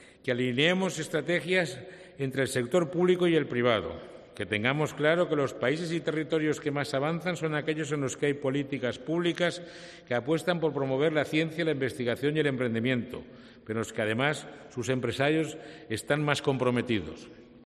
De este modo, ha resaltado, en la ceremonia de entrega de los Premios Jaume I 2023, presidida este martes por el Rey Felipe VI, que esto "contribuirá a aumentar el empleo, la calidad de vida de los ciudadanos y ayudará a cortar la brecha con Europa y los países líderes del mundo".